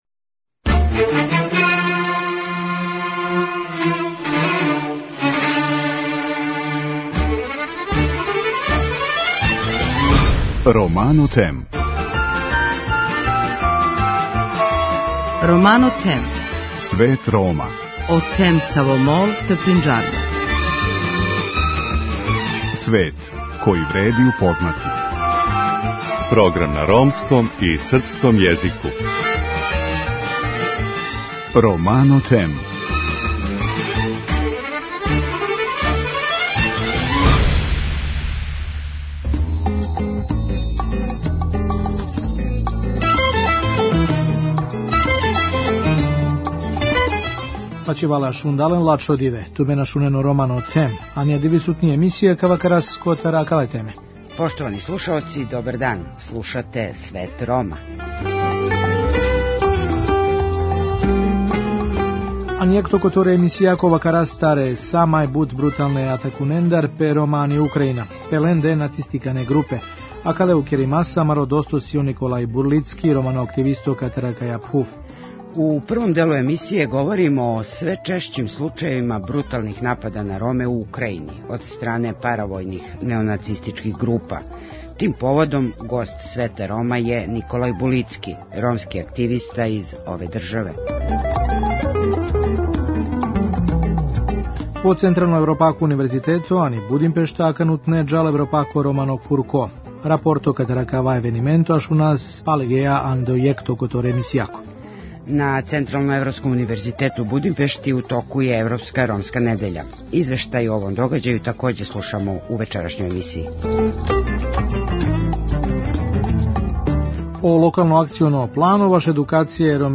На Централноевропском Универзитету у Будимпешти у току је Европска ромска недеља. Извештај о овом догађају чућемо у првом делу данашње емисије.